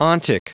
Prononciation du mot ontic en anglais (fichier audio)
Prononciation du mot : ontic